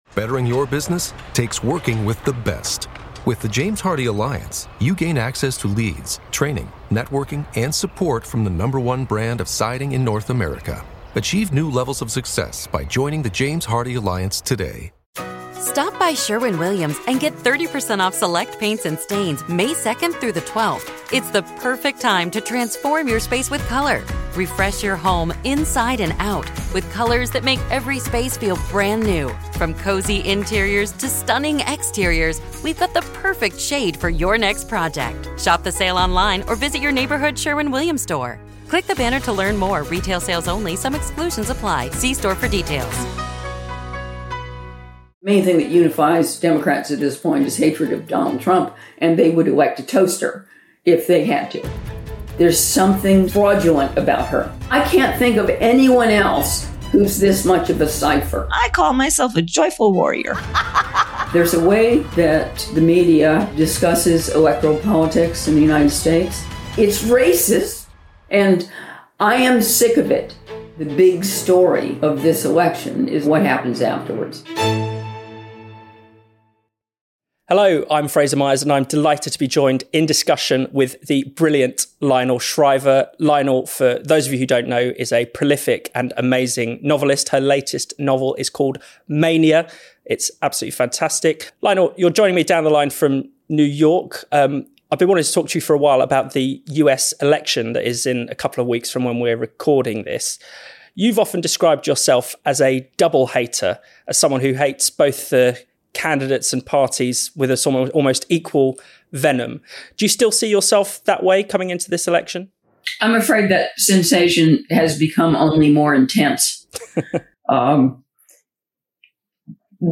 This is the audio from a video we have just published on our YouTube channel – an interview with Lionel Shriver.